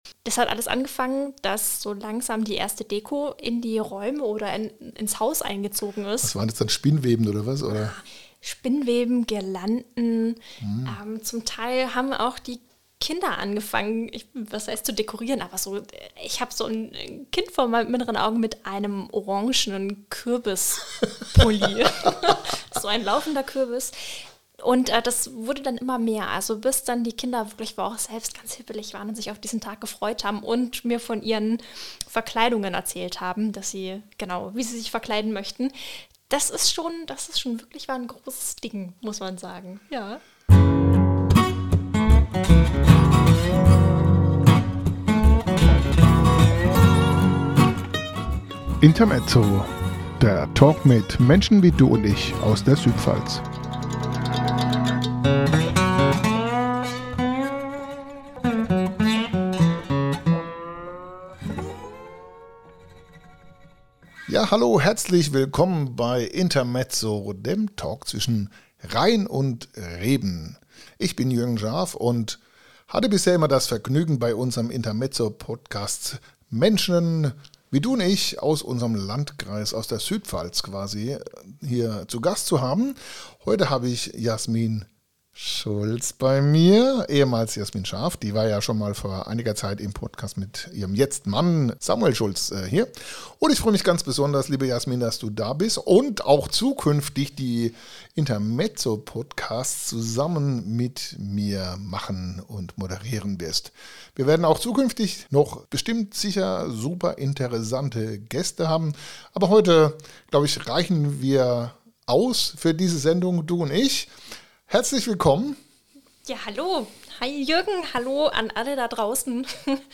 Intermezzo - der Talk zwischen Rhein und Reben aus der Südpfalz